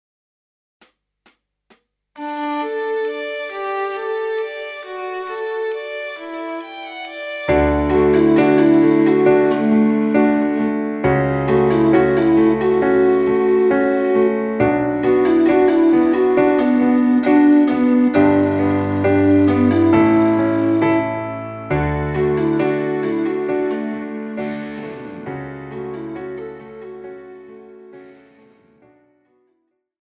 Žánr: Rock
BPM: 135
Key: D
MP3 ukázka s ML